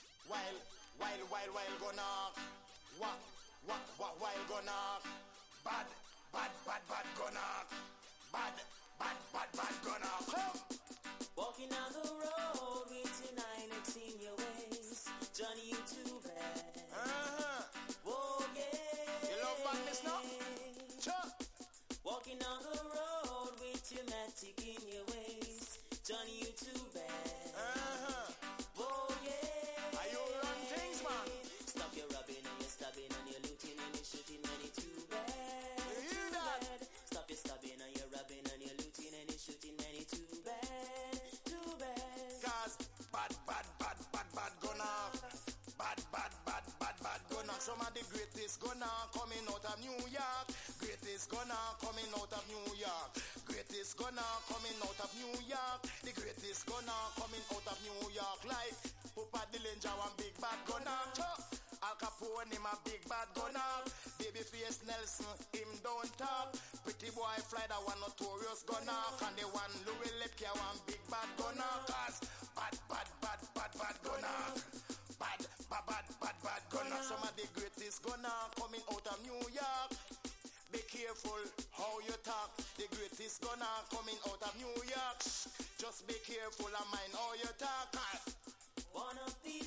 • REGGAE-SKA
# DANCE HALL